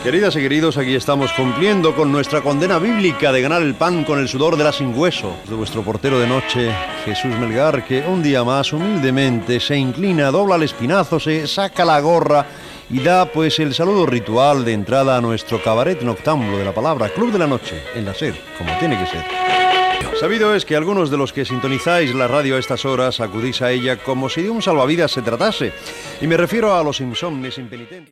Presentació del programa